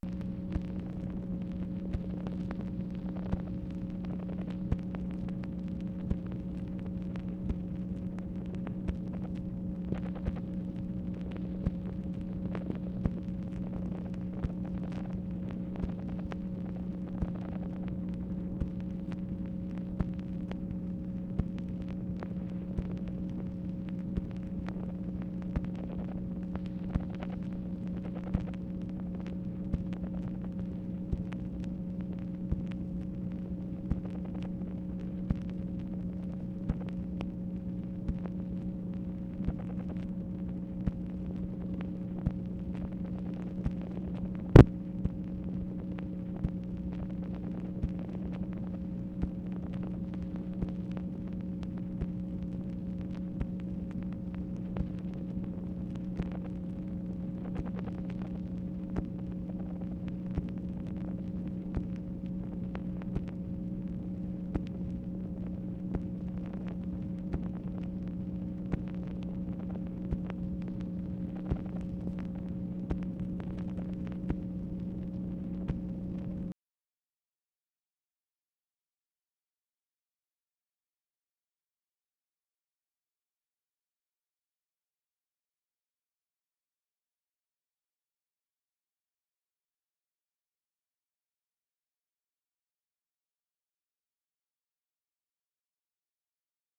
MACHINE NOISE, May 23, 1966
Secret White House Tapes | Lyndon B. Johnson Presidency